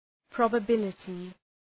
Προφορά
{,prɒbə’bılətı}